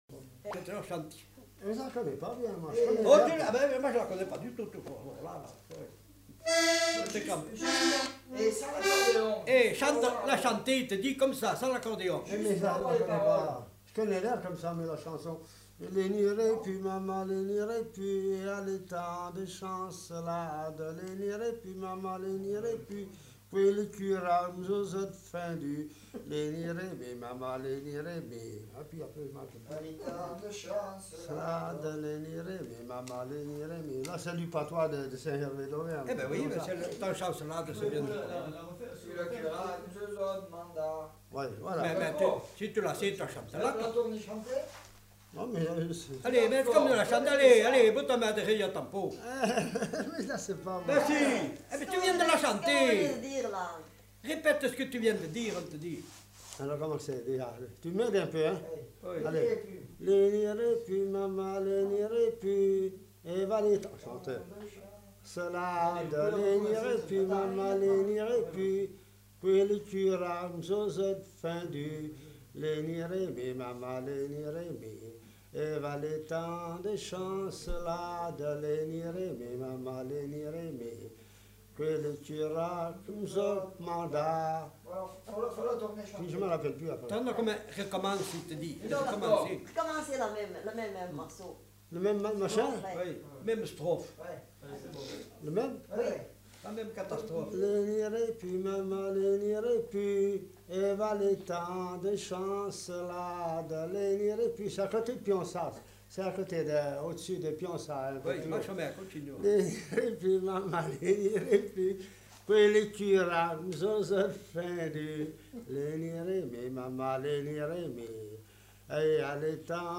Aire culturelle : Auvergne
Lieu : Peschadoires
Genre : chanson-musique
Type de voix : voix d'homme
Production du son : chanté
Instrument de musique : accordéon